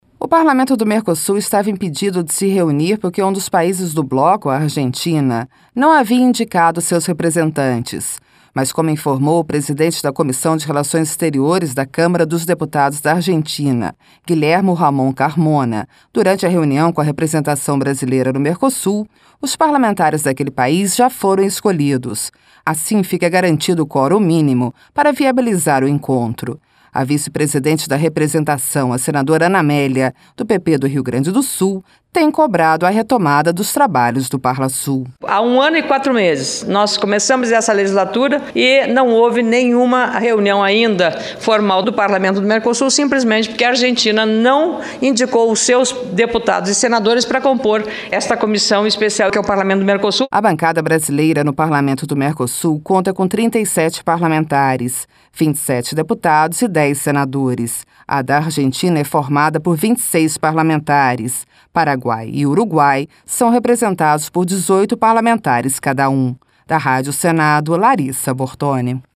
RadioAgência